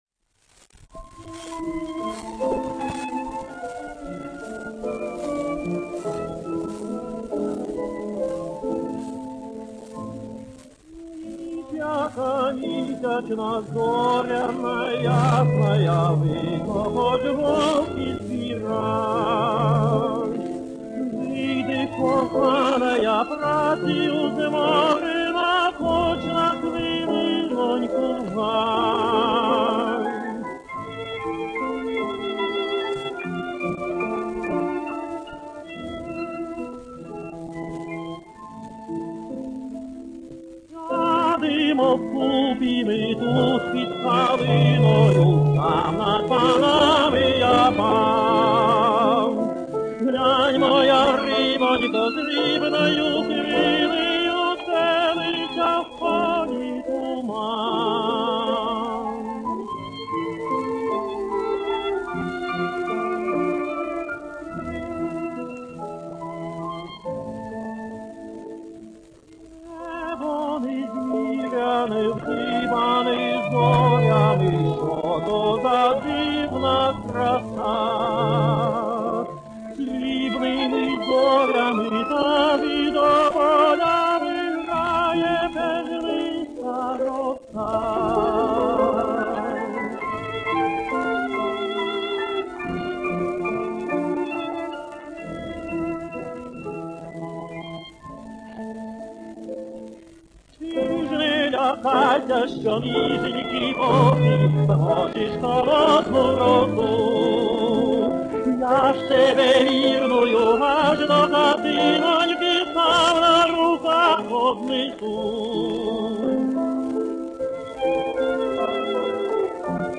фортепиано
скрипка
виолончель
Романс «Hiч яка мiсячна»
Запись 1937 года (грампластинка Ногинск B-5291)